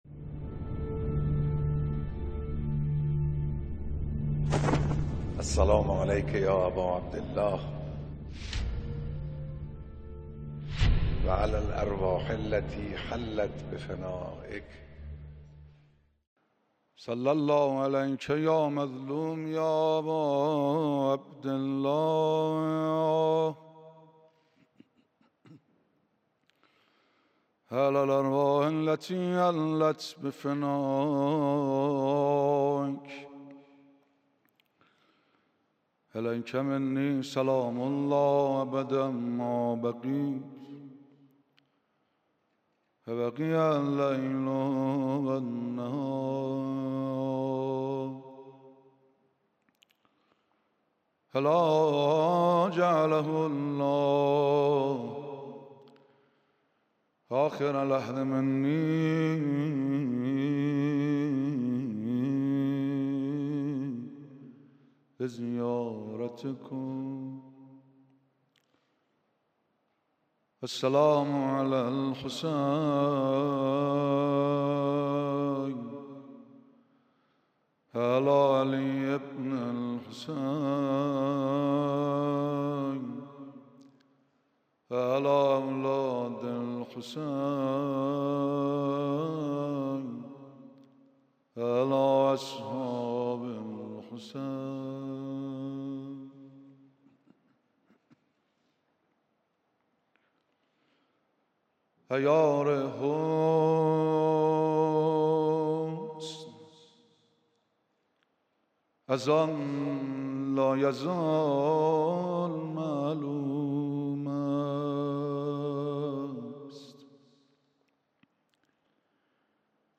دومین شب مراسم عزاداری حضرت اباعبدالله الحسین علیه‌السلام
مداحی